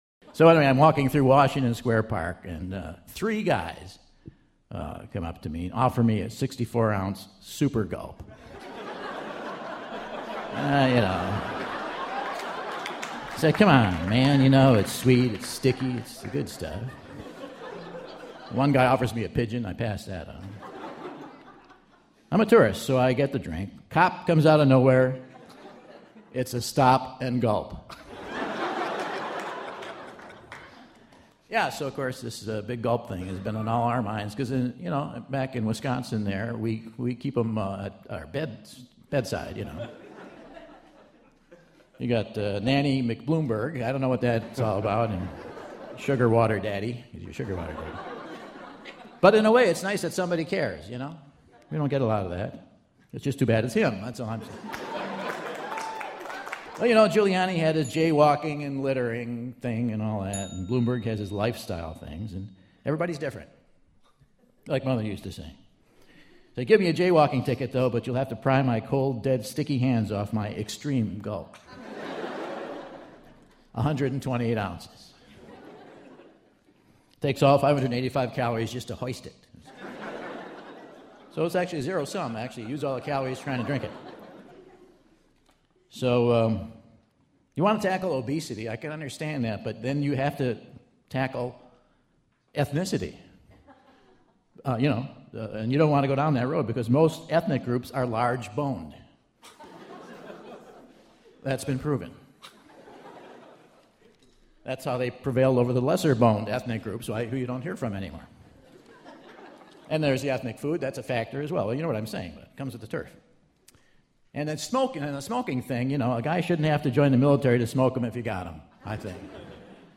June 9, 2012 - New York City, NY - Skirball Center for the Performing Arts | Whad'ya Know?